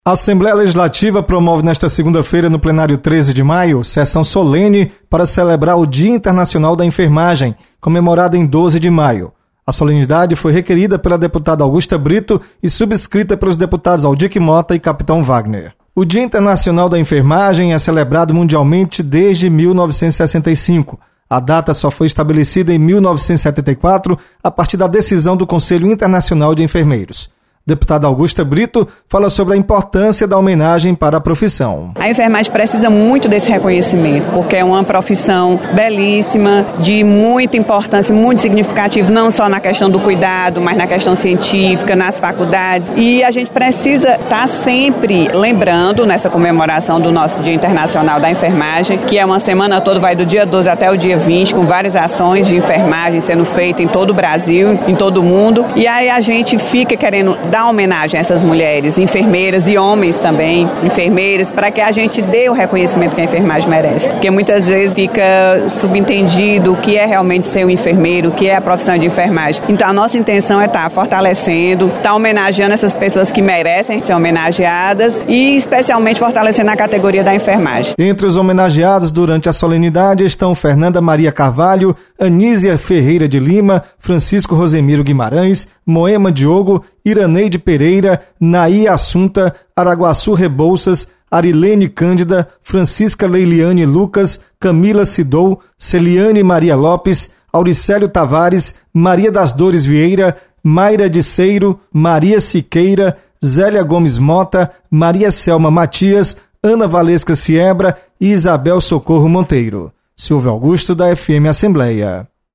Assembleia promove solenidade em comemoração ao Dia Internacional de Enfermagem. Repórter